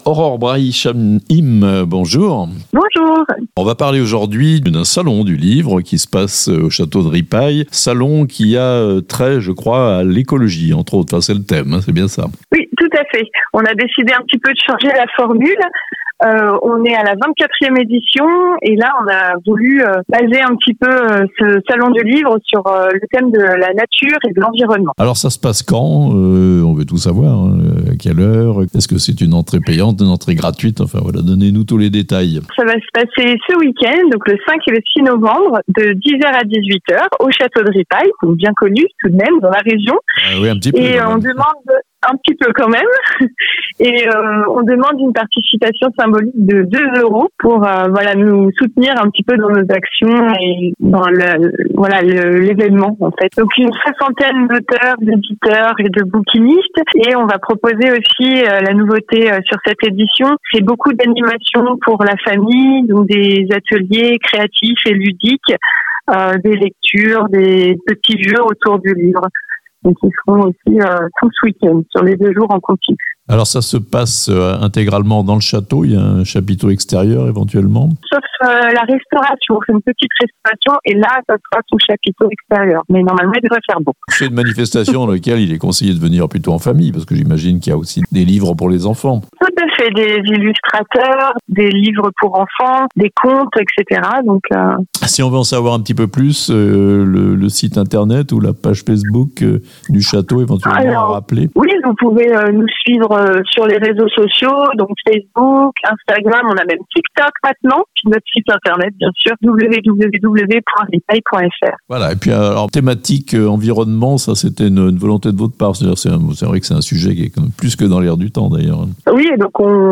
La nature et l'environnement en fête au 24ème salon du livre au château de Ripaille, les 5 et 6 novembre (interview)